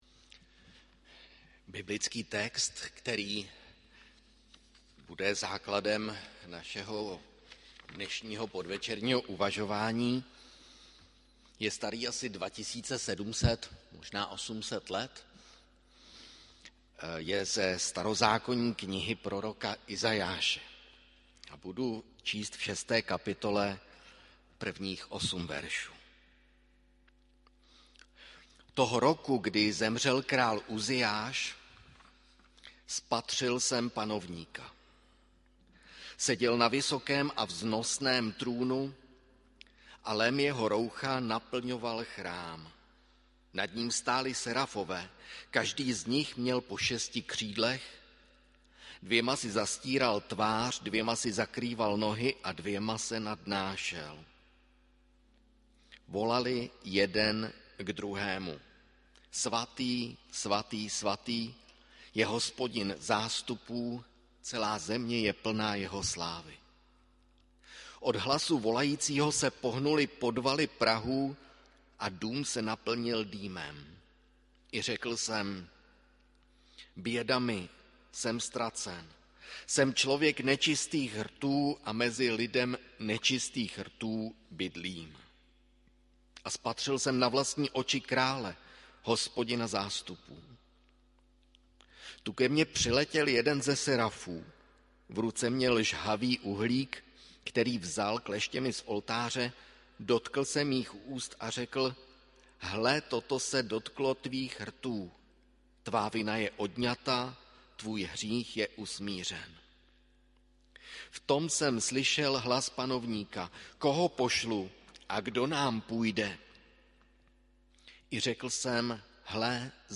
Hudební nešpory 6. 2. 2022 • Farní sbor ČCE Plzeň - západní sbor
Příspěvek byl publikován v rubrice Kázání .